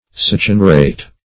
Succinurate \Suc`cin*u"rate\, n.
succinurate.mp3